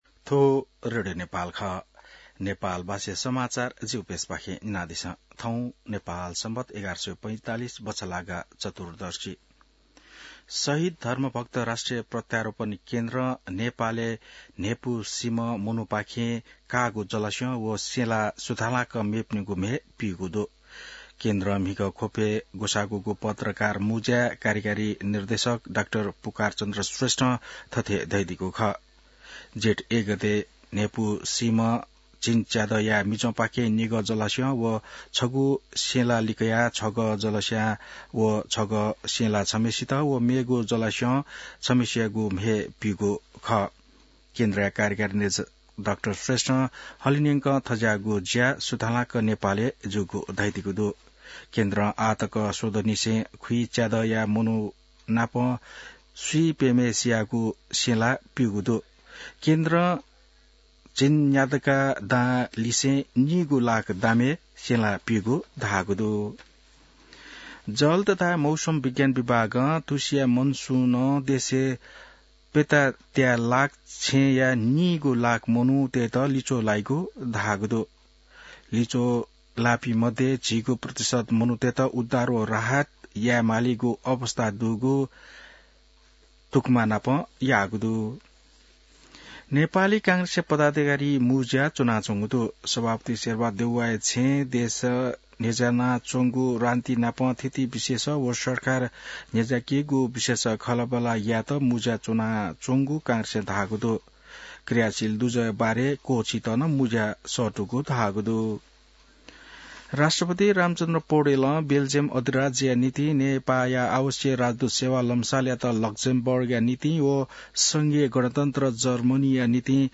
नेपाल भाषामा समाचार : १२ जेठ , २०८२